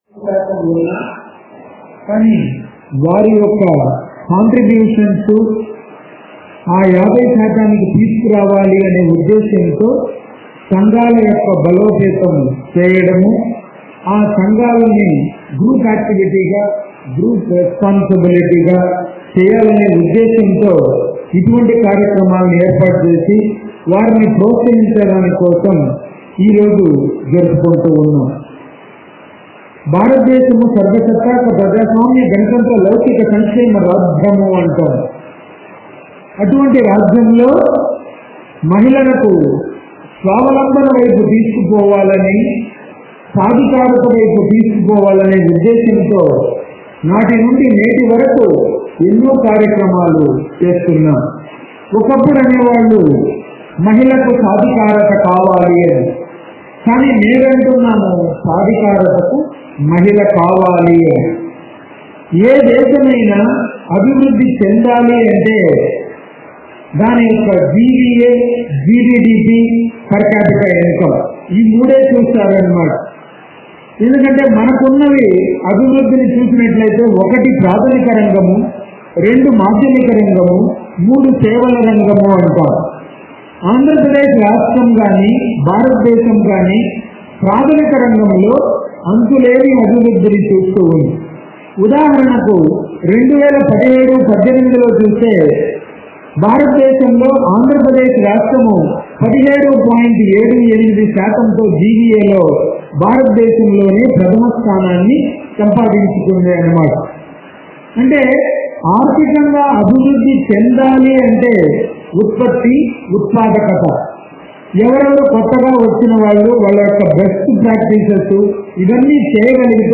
ఈరోజు 15 అక్టోబర్ 2018న మహిళా రైతు దినోత్సవం సందర్భంగా విజయవాడలో బృందావన్ కాలనీ, ఏ 1 కన్వెన్షన్ హాల్లో ఏర్పాటు చేసిన సదస్సును కలెక్టర్ బి లక్ష్మీకాంతం చేతుల మీదుగా ప్రారంభించారు. ఈ కార్యక్రమంలో వివిధ రకాల సేంద్రియ వ్యవసాయ పద్ధతులు, కూరగాయలు, విత్తనాలను, మోటర్ పంపులను ప్రదర్శనలో ఉంచారు.